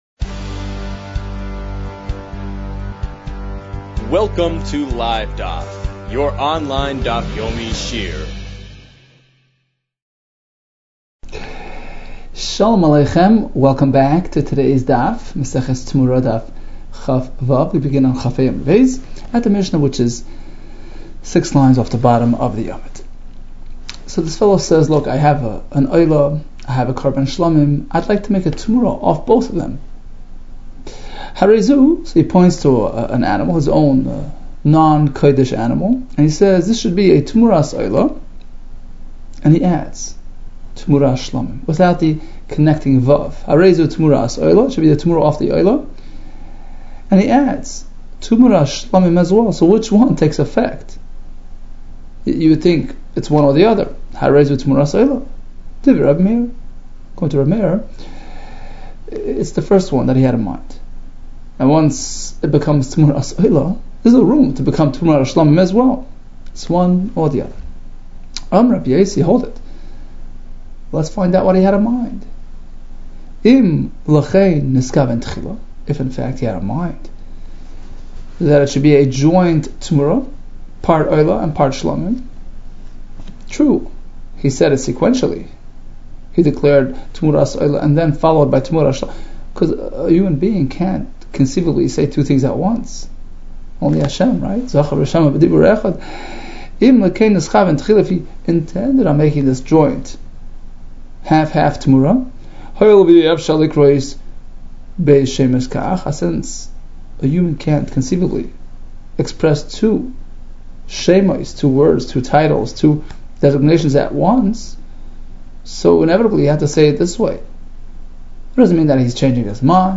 Temurah 26 - תמורה כו | Daf Yomi Online Shiur | Livedaf